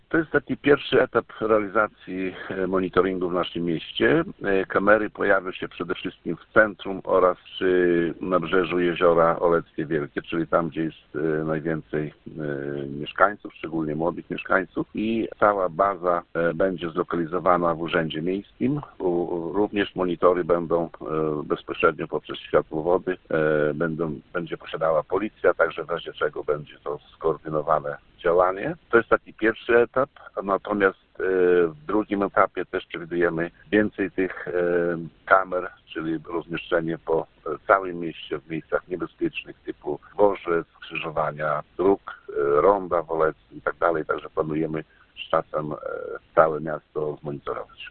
– Za te pieniądze zakupionych zostanie 6 kamer, a siedzibie Urzędu Miejskiego powstanie system rejestracji obrazów skomunikowany z Komendą Powiatową Policji w Olecku, mówi Wacław Olszewski, burmistrz miasta.